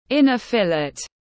Thịt thăn trong tiếng anh gọi là inner fillet, phiên âm tiếng anh đọc là /ˈɪnə ˈfɪlɪt/
Inner fillet /ˈɪnə ˈfɪlɪt/